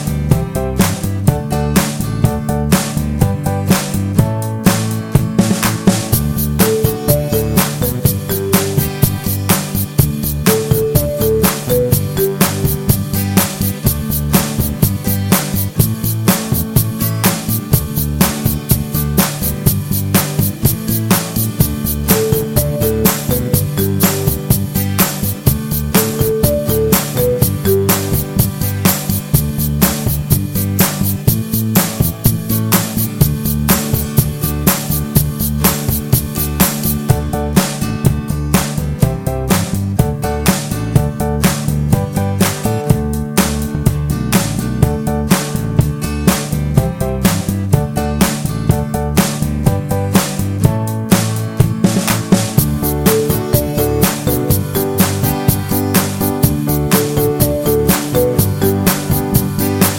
Professional Pop (1960s) Backing Tracks.